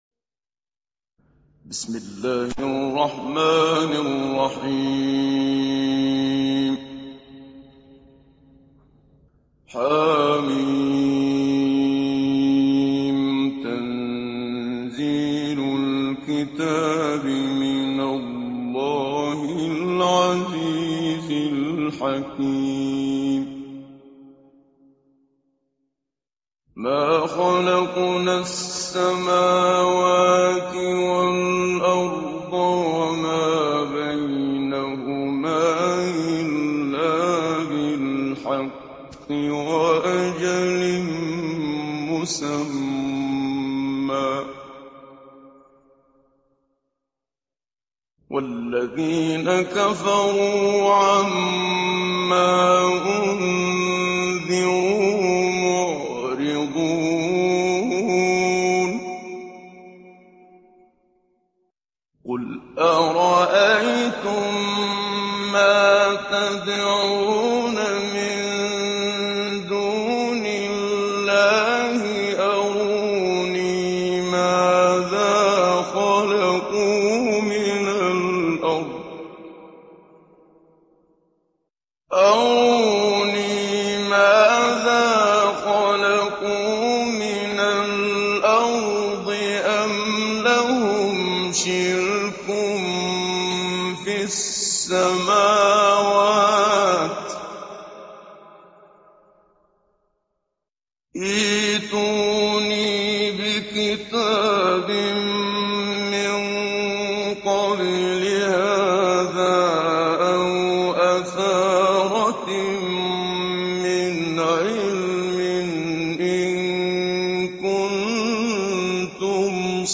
Download Surah Al Ahqaf Muhammad Siddiq Minshawi Mujawwad